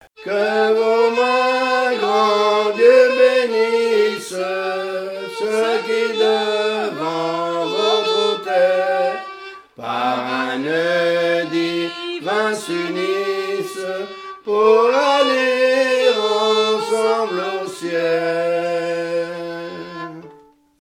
Cantique d'entrée d'une messe de mariage
Pièce musicale inédite